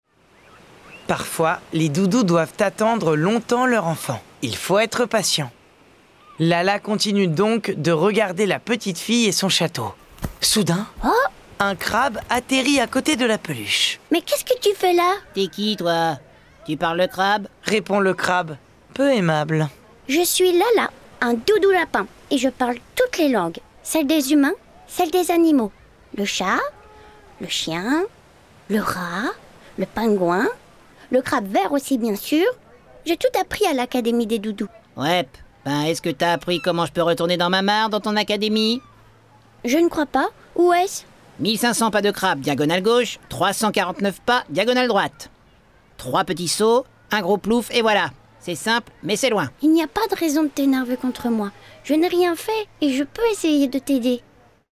« L’académie des doudous » de Sandra le Guen, raconté par 4 comédiens.